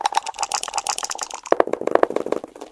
dice.wav